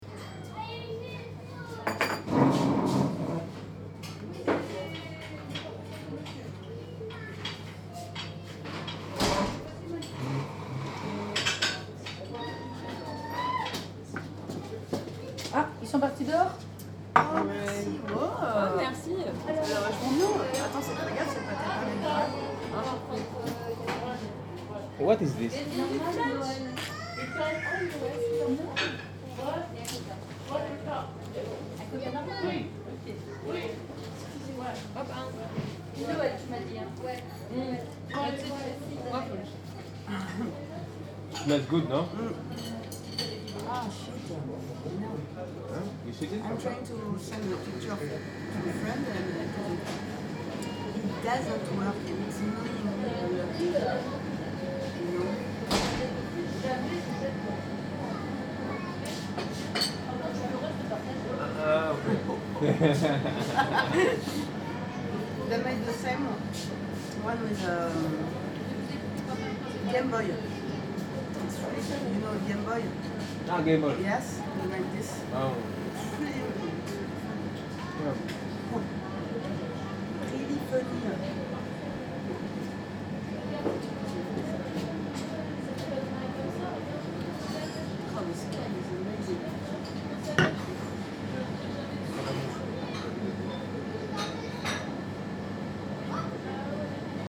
01/01/2015 17:00 Pas de Calais, Wimereux.
Nous marchons sur une immense plage plate, lumineuse, battue par le vent.
Nous nous réfugions sur la digue, dans un café qui sent la gaufre.